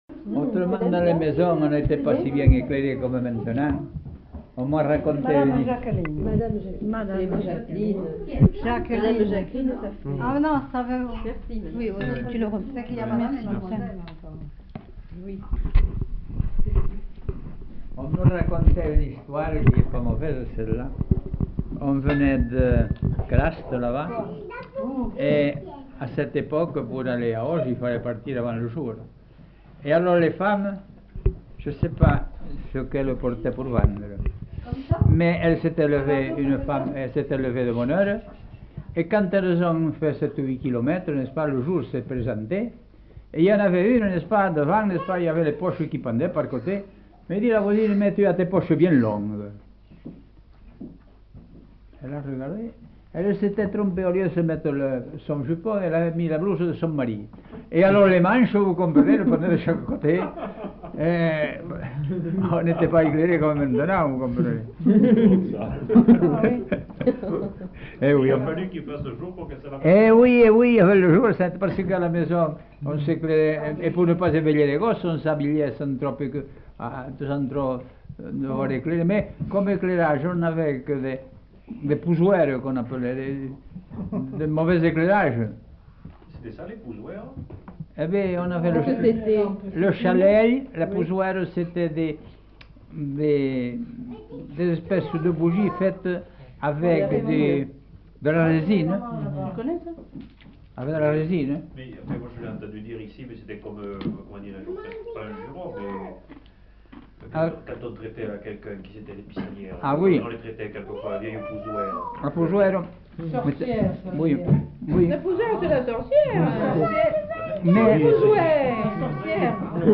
Lieu : Montaut
Genre : témoignage thématique